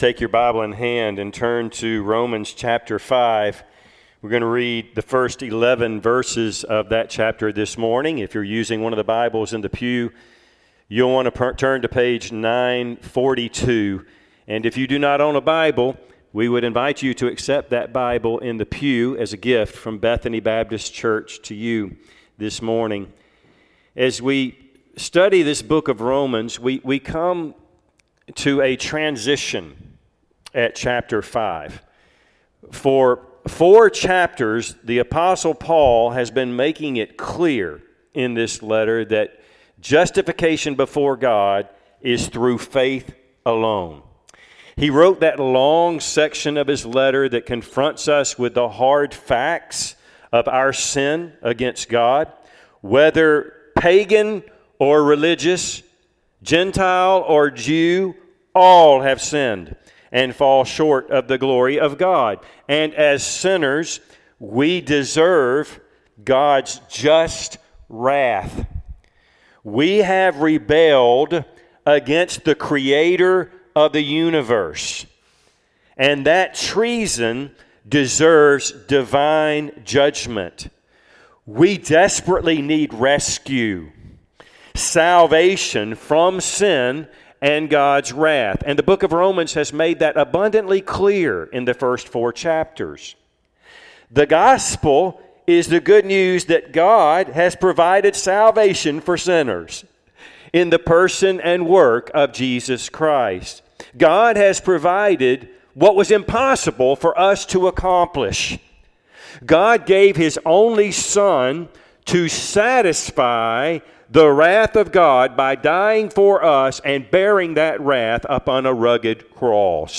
Passage: Romans 5:1-11 Service Type: Sunday AM